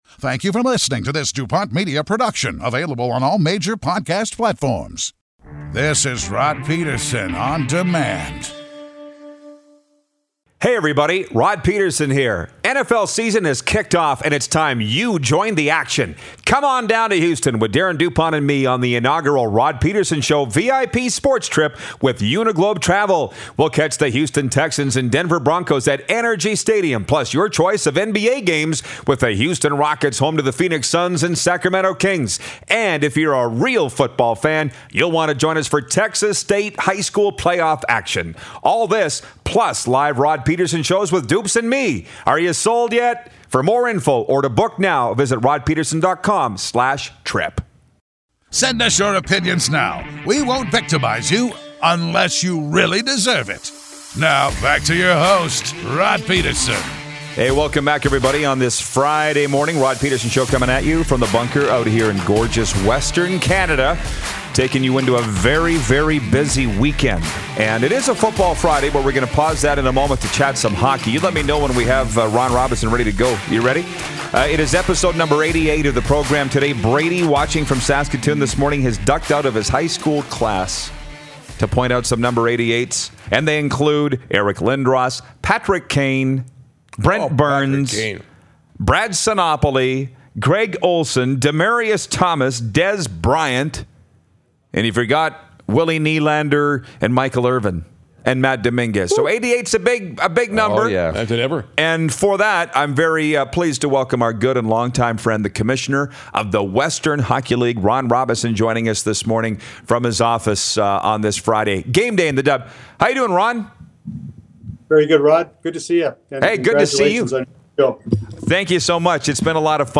When we hit 88 episodes… you’re gonna see some serious sports talk!